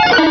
Cri de Grodoudou dans Pokémon Rubis et Saphir.